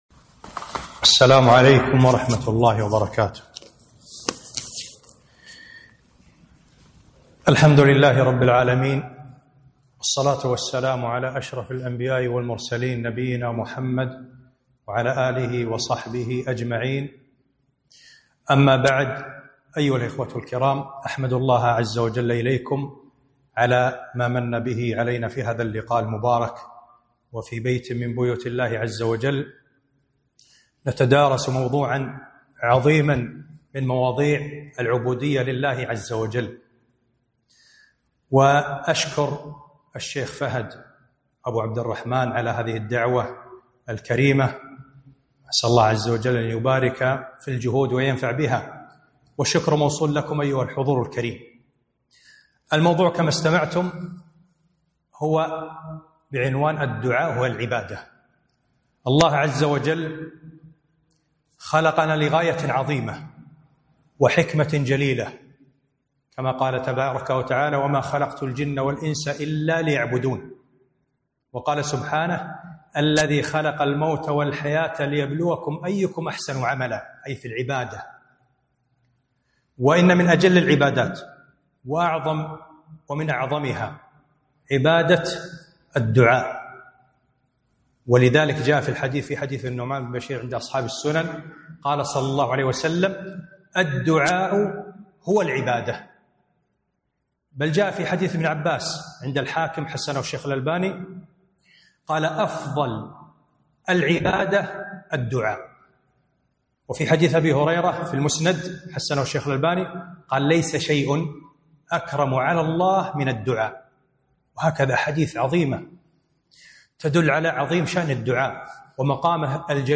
محاضرة - الدعاء هو العبادة